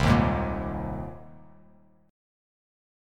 Cm7#5 chord